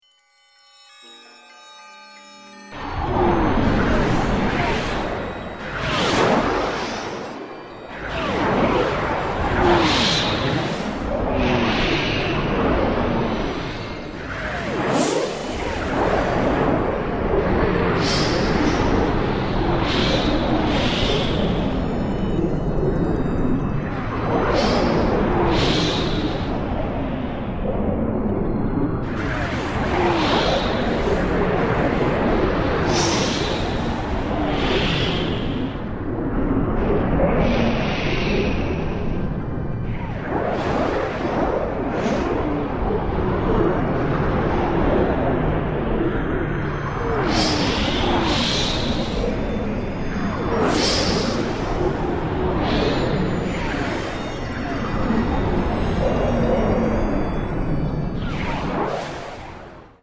Composition for synthesizer
A new all-electronic CD